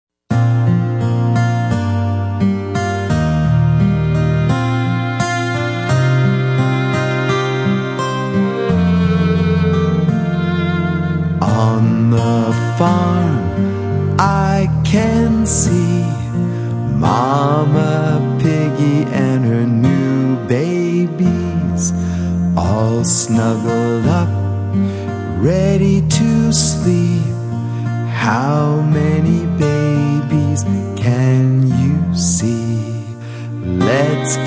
Lullaby for Bedtime or Naptime